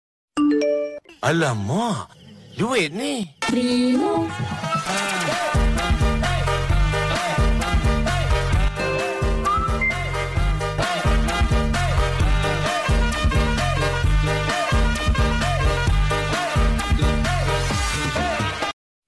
Nada dering panggilan Tag